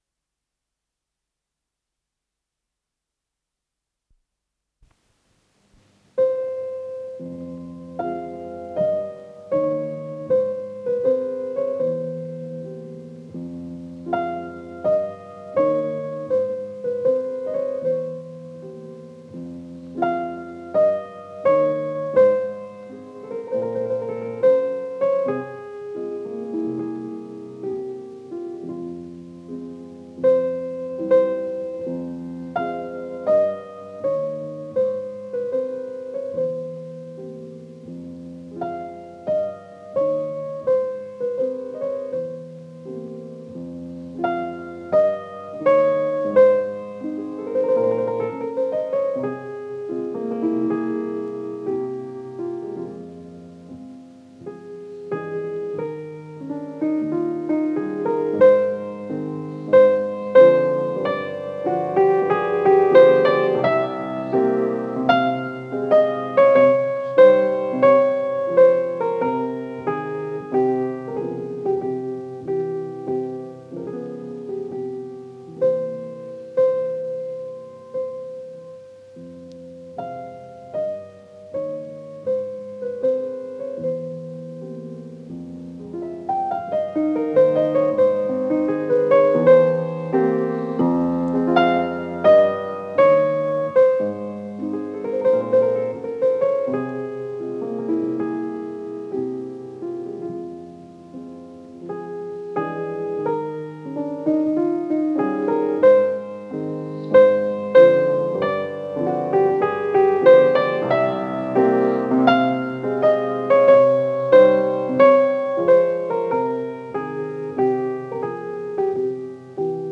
с профессиональной магнитной ленты
Скорость ленты38 см/с
Тип лентыORWO Typ 104